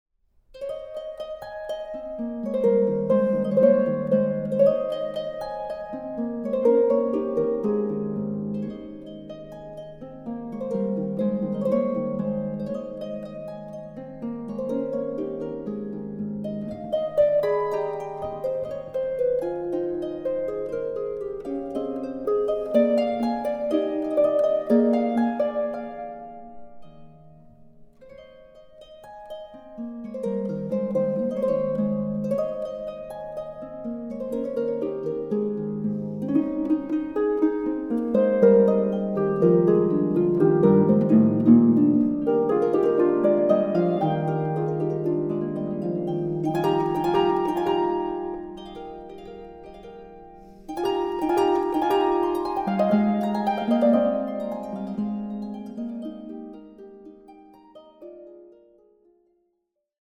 Harfe
Aufnahme: Festeburgkirche Frankfurt, 2024